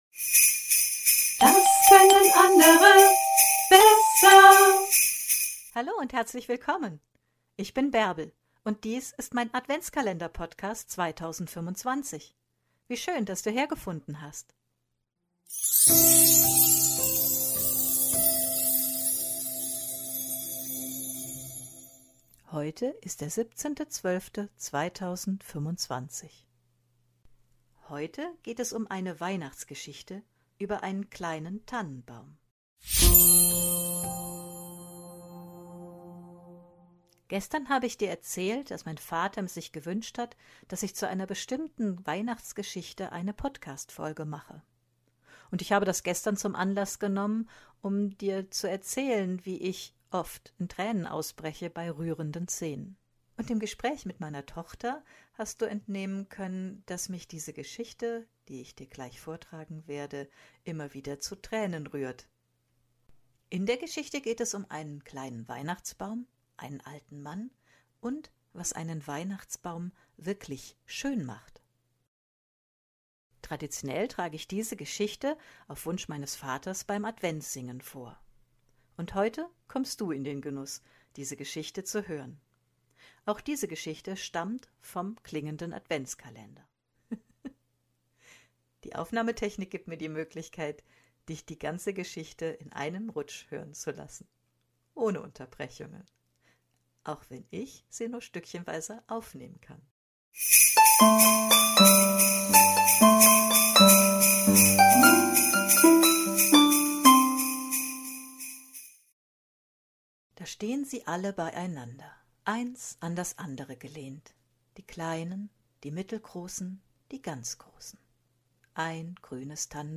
Heute trage ich die Geschichte von dem kleinen Weihnachtsbaum vor